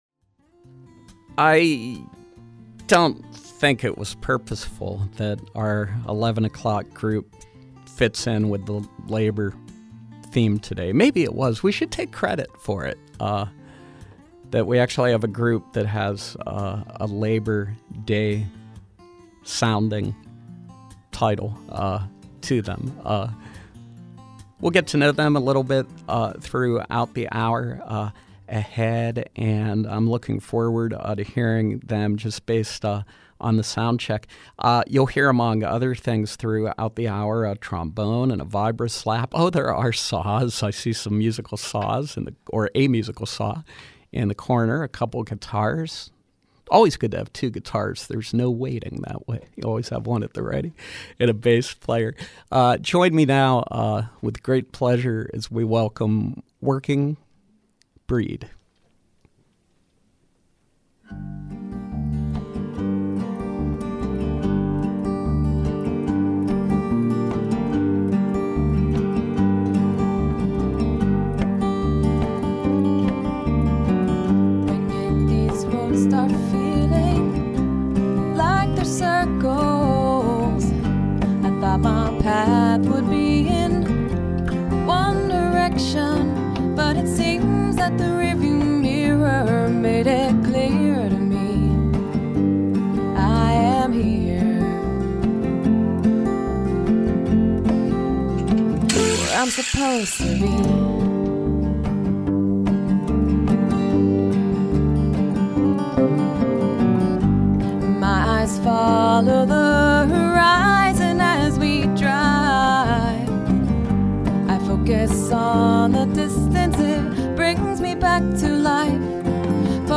The multi-instrumental musical stylings